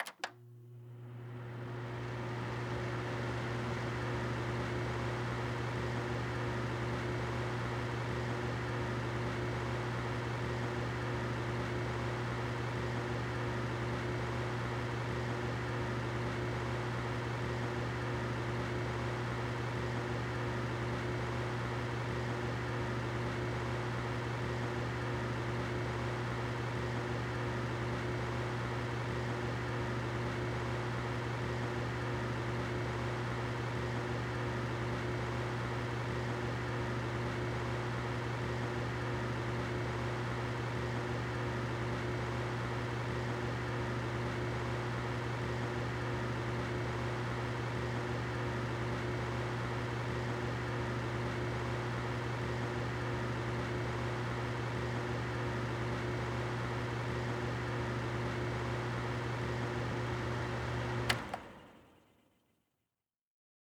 household
Humidifier On Run Off